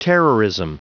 Prononciation du mot terrorism en anglais (fichier audio)
Prononciation du mot : terrorism